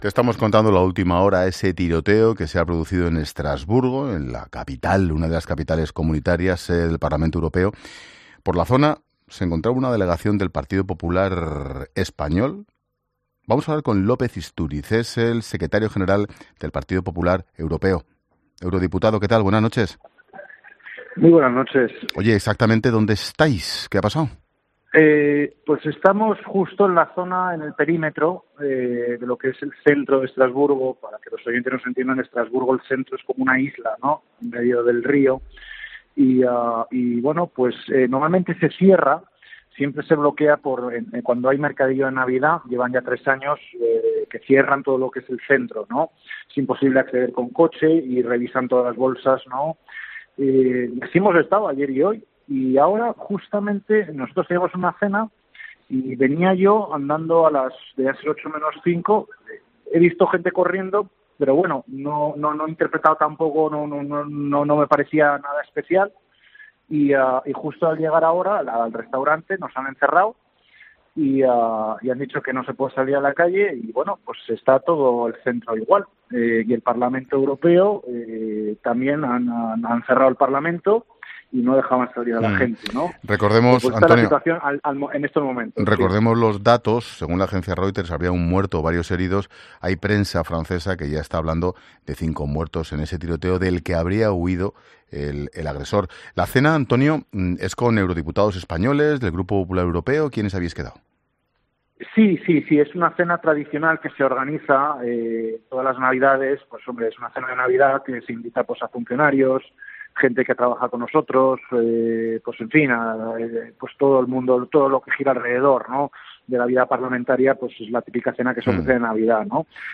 En 'La Linterna' estuvo Antonio López-Istúriz, diputado del Partido Popular en el Parlamento Europeo y secretario general del Partido Popular europeo, que se encontraba en la zona donde había ocurrido el tiroteo para explicar de primera mano lo que estaba ocurriendo en la ciudad gala: “Hemos visto gente correr pero nos ha parecido algo normal.